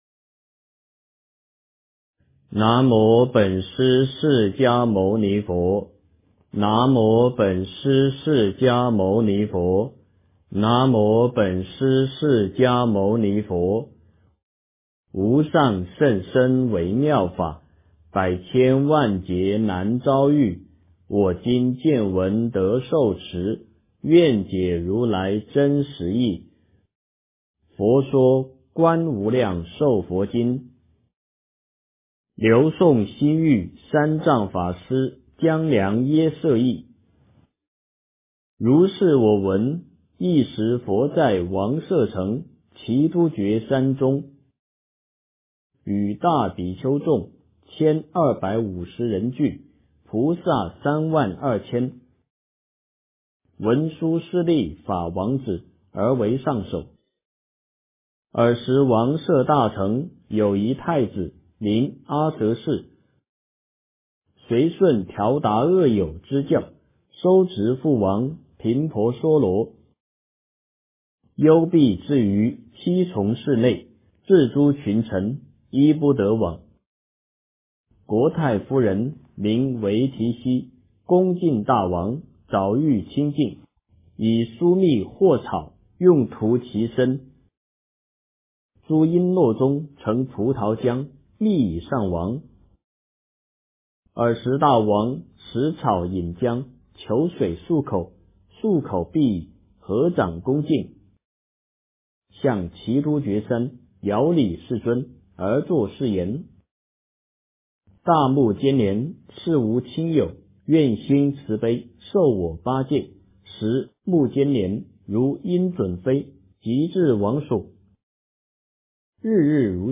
佛说观无量寿佛经 - 诵经 - 云佛论坛